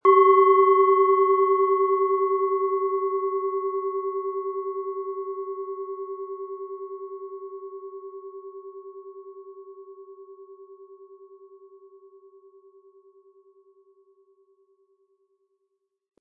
Planetenschale® Erdung & Erde Dich mit Tageston, Ø 11,3 cm, 180-260 Gramm inkl. Klöppel
Durch die traditionsreiche Herstellung hat die Schale stattdessen diesen einmaligen Ton und das besondere, bewegende Schwingen der traditionellen Handarbeit.
MaterialBronze